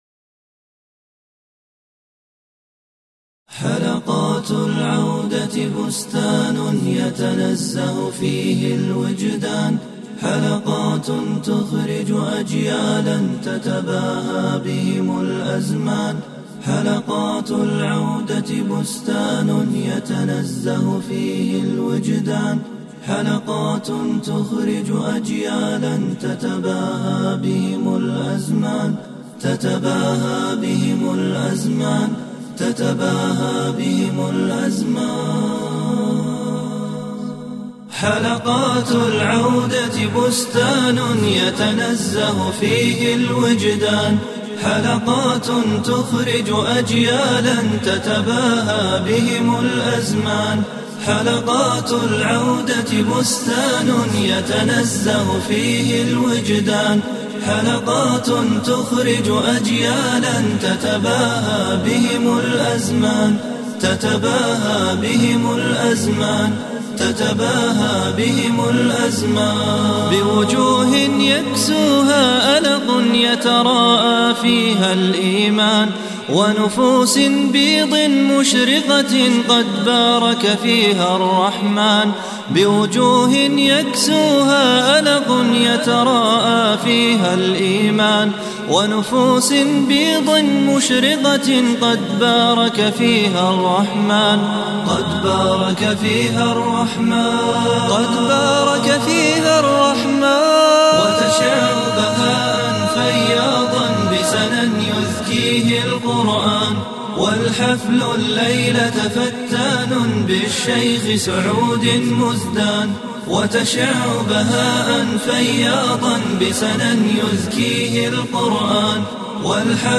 أنشودة خاصة بحفل مجمع حلقات العودة بالرياض قبل أربع سنوات !
إنشاد المنشد